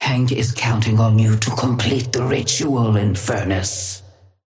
Sapphire Flame voice line - Hank is counting on you to complete the ritual, Infernus.
Patron_female_ally_inferno_start_02.mp3